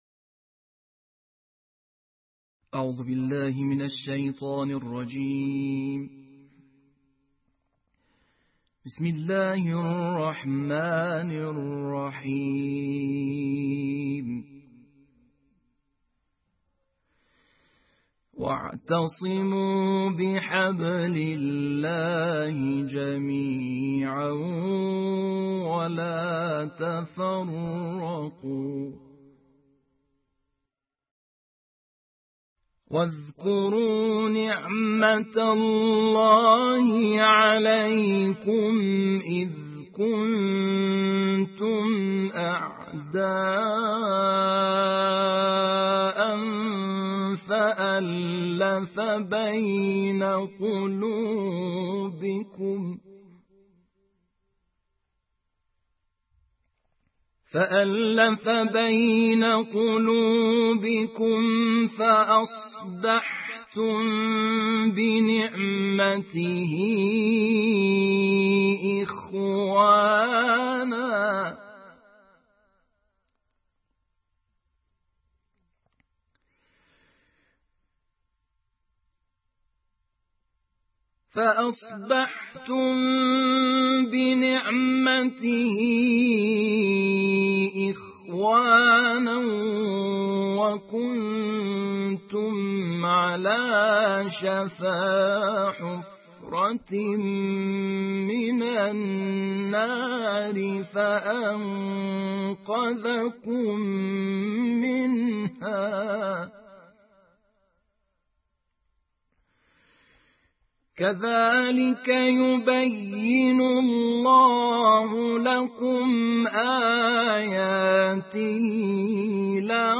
تلاوت کوتاه مجلسی